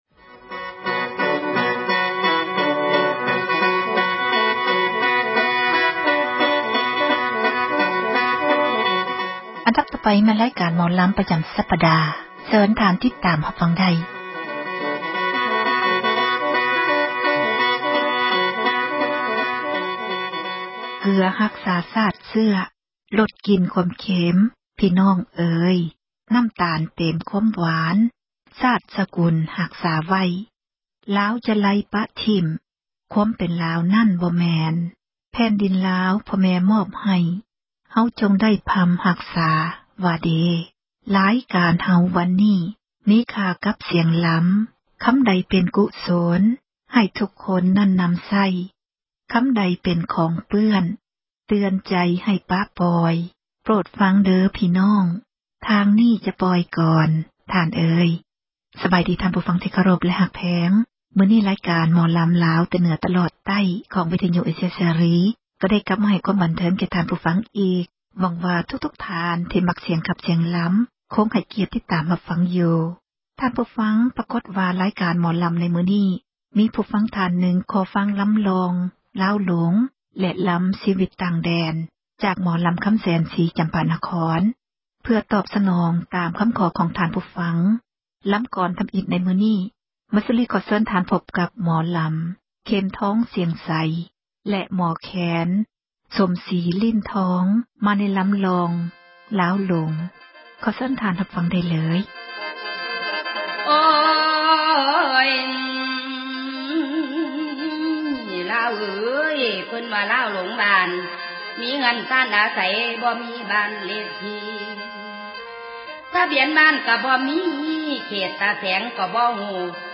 ຣາຍການ ໝໍລໍາ